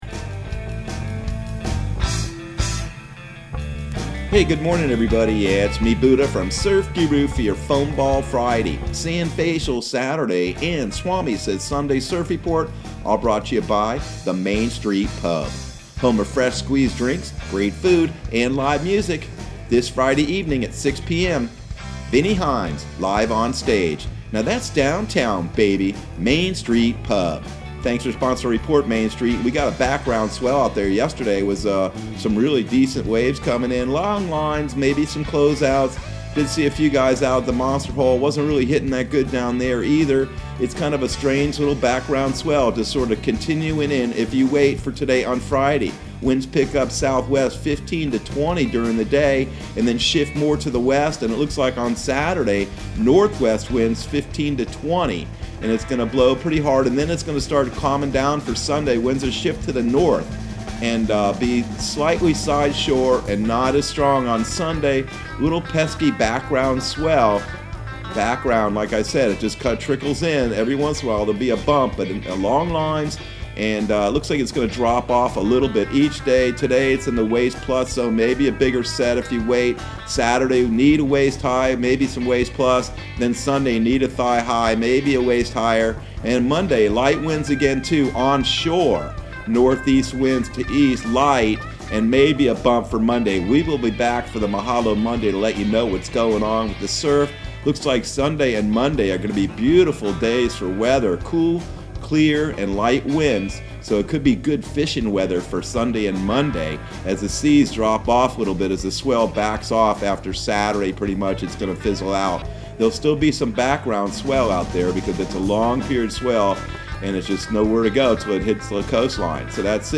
Surf Guru Surf Report and Forecast 01/08/2021 Audio surf report and surf forecast on January 08 for Central Florida and the Southeast.